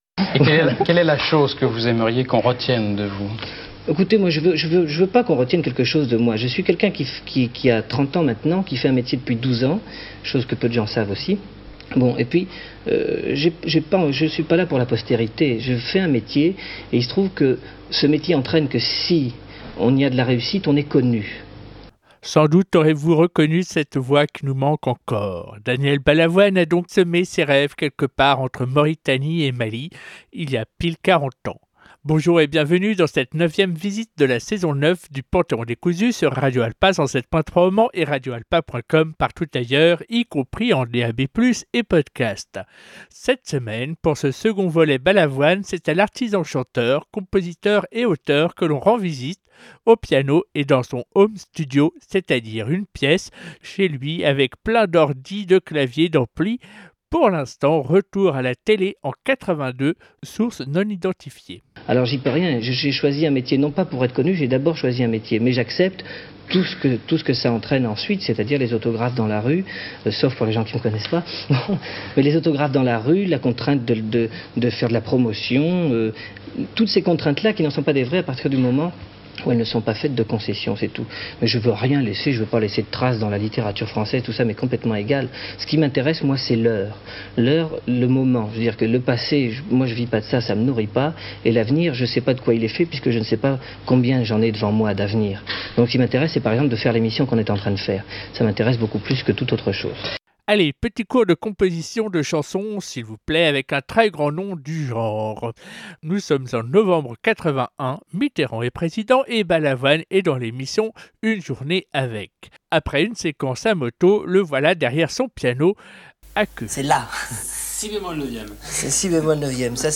Ensuite l’ambiance s’oriente vers de la Soul des années 50′ à aujourd’hui ! De la funk à la soft en passant par la romance.